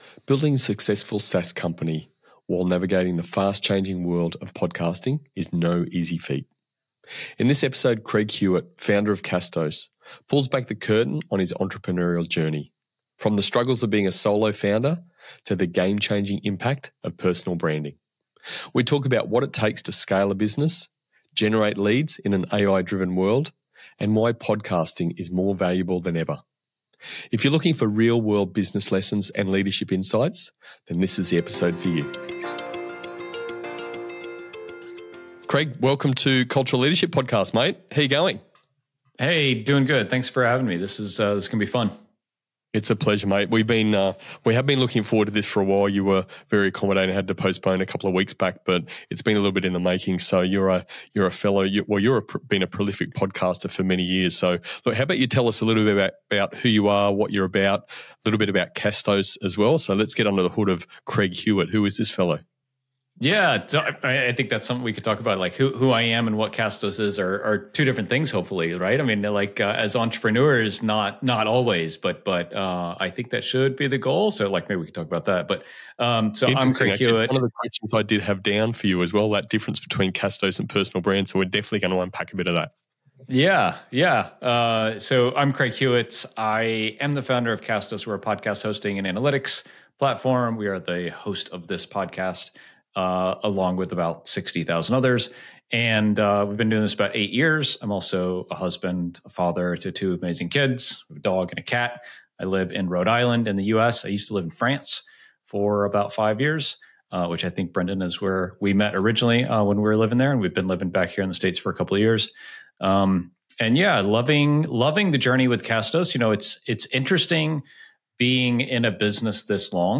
Tune in for an honest conversation about leadership, growth, and the evolving podcasting landscape.